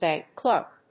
アクセントbánk clèrk